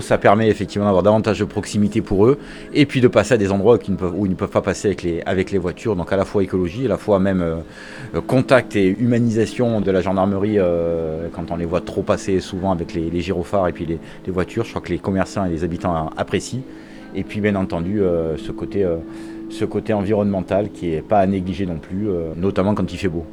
Des avantages non négligeables par rapport aux voitures, que détaille Jean-Philippe Mas, le président de la communauté de communes.
ITG Jean Philippe Mas 1 - vélo gendarmerie Scionzier_WM.wav